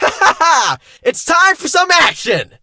fang_start_vo_02.ogg